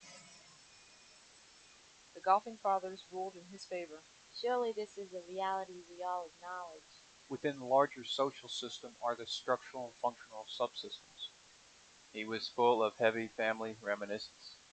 These audio examples compare single channel noise reduction using Martin's Minimum Statistics [1], the bias compensated MMSE approach [2], and the proposed SPP approach.
outputmswhite.mp3